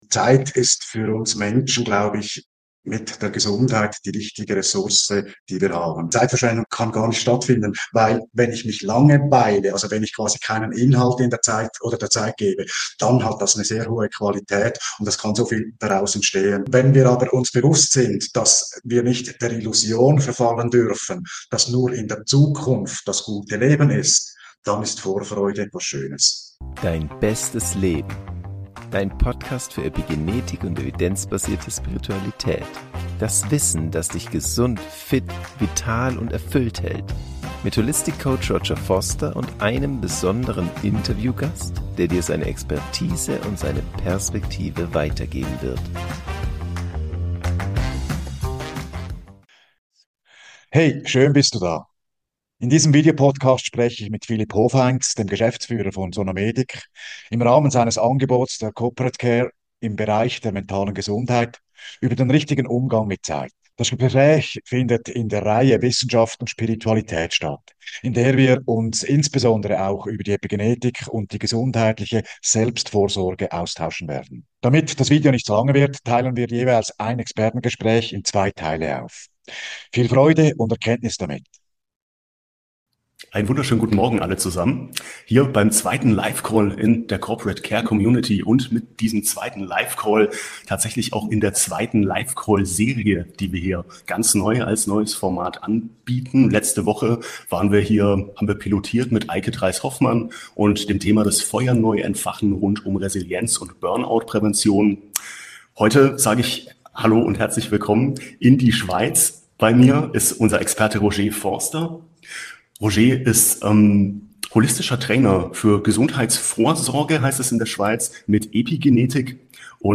Expertentalk: Selbstbestimmte Zeit | Der richtige Umgang mit Zeit ~ Dein bestes Leben: Evidenzbasierte Spiritualität und Epigenetik Podcast